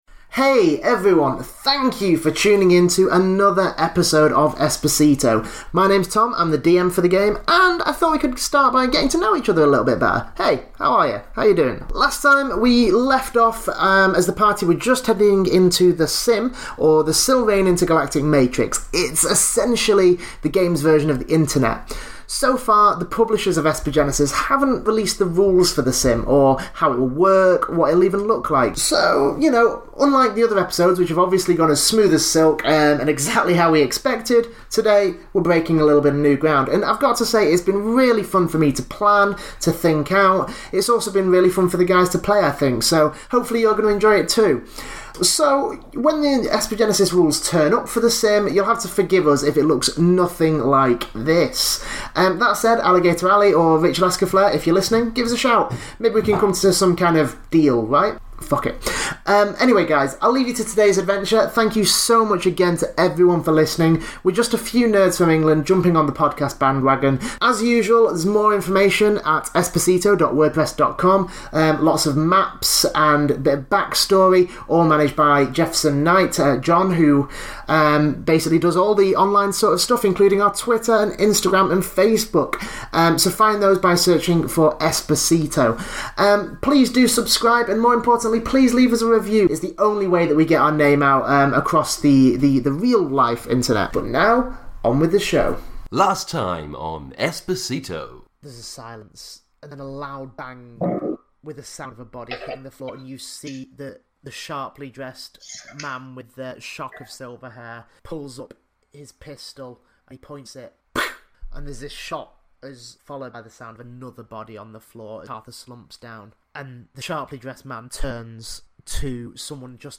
This, the only live action Esper Genesis podcast, tells the ongoing saga of The Epsilon Shift, an innocent group of idiots who have been framed for a terrorist attack.